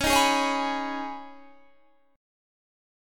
Db7sus2 chord